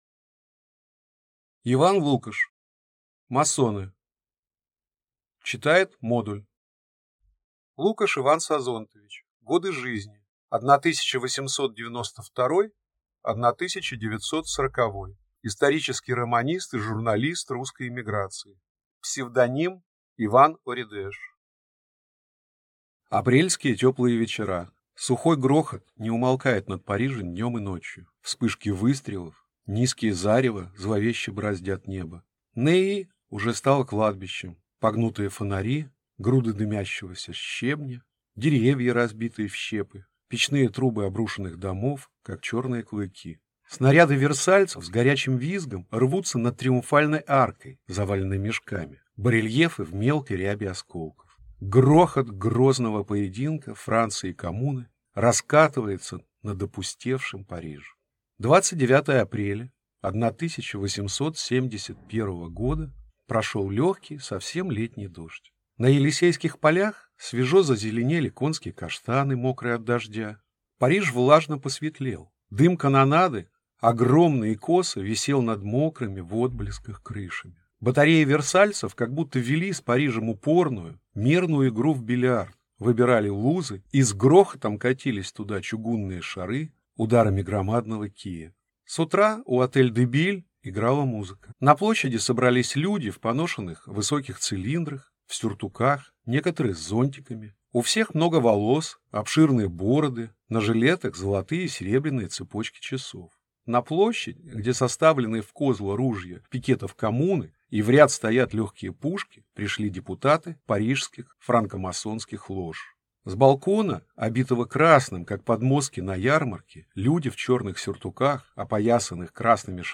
Аудиокнига Масоны | Библиотека аудиокниг